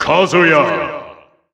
The announcer saying Kazuya's name in English and Japanese releases of Super Smash Bros. Ultimate.
Kazuya_English_Announcer_SSBU.wav